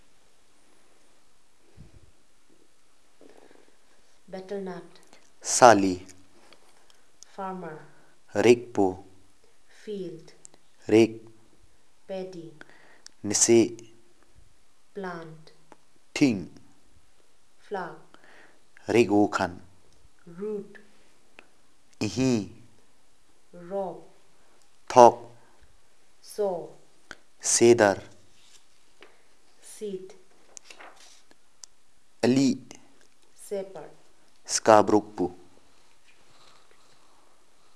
Elicitation of words about occupation, herbs, grasses, shrubs, weeds, fruits and seeds